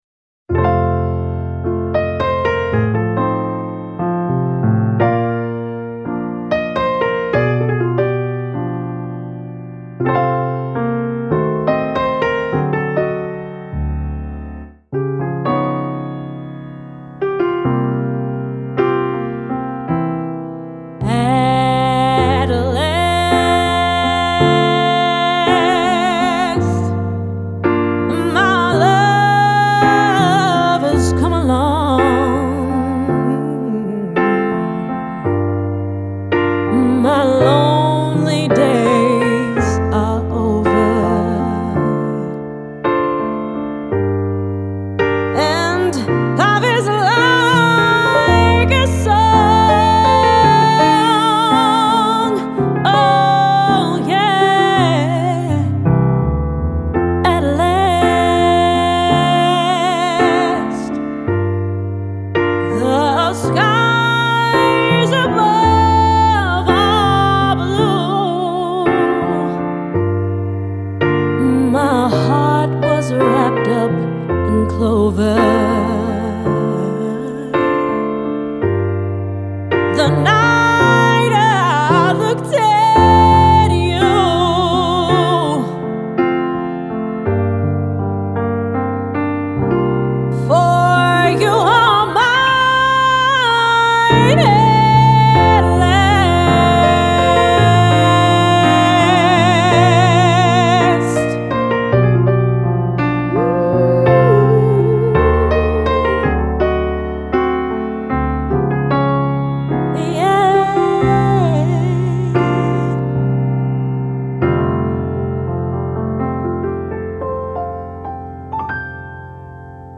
DJ | Piano & Vocals | Feature Vocals
01:53 Songlist Open format, can play any genres and style.